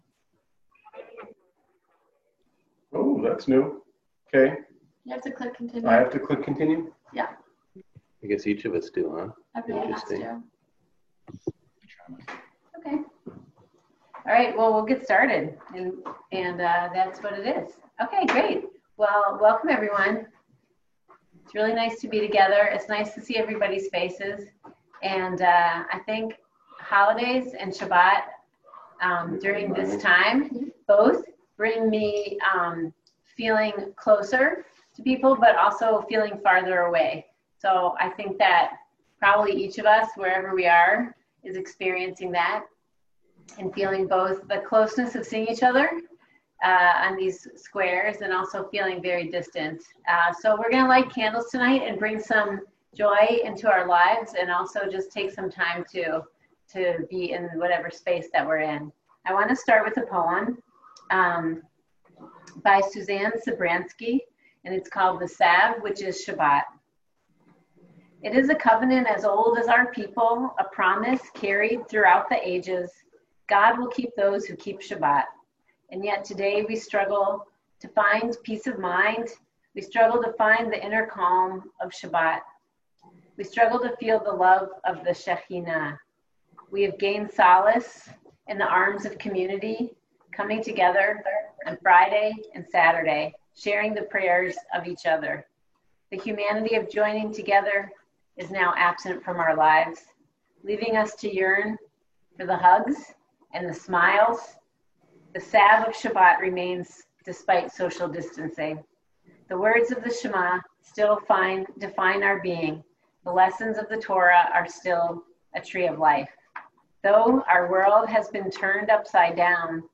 UW Hillel April 10 Facebook Shabbat Livestream (untitled)